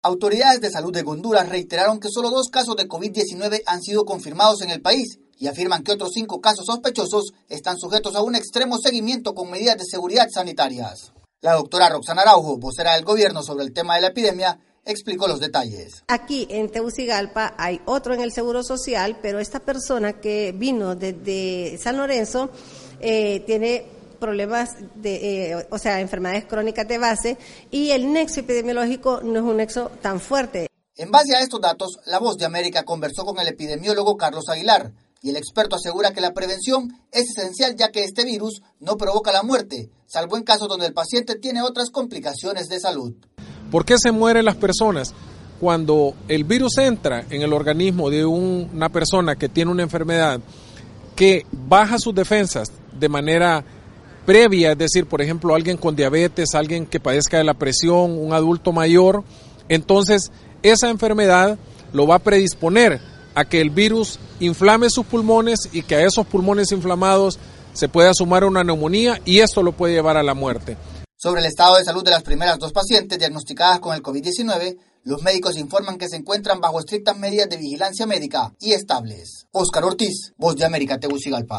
VOA: Informe de Honduras